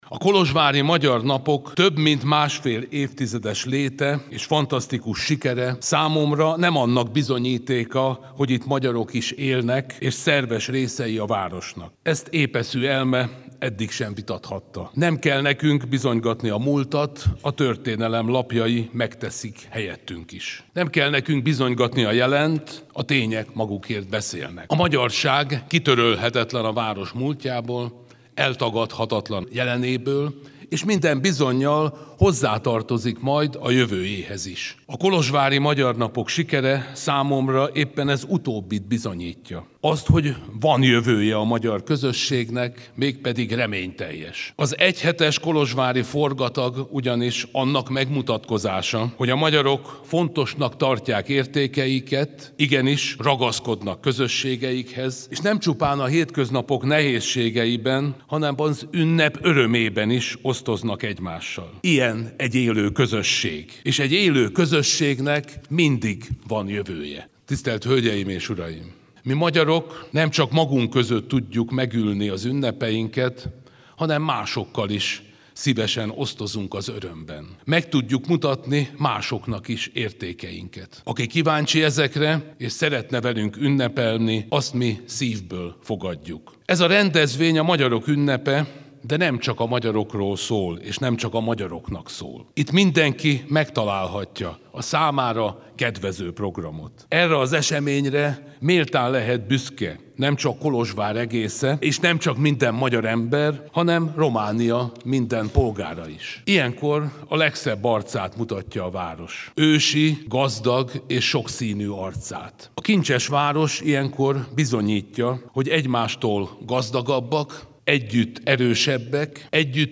A Kolozsvári Magyar Napok több mint másfél évtizedes léte és sikere azt bizonyítja, hogy a magyarság kitörölhetetlen a város múltjából, eltagadhatatlan a jelenéből, és minden bizonnyal hozzátartozik majd a jövőjéhez is – mondta Sulyok Tamás Magyarország köztársasági elnöke a Kolozsvári Magyar Operában a magyar napok megnyitó ünnepségén.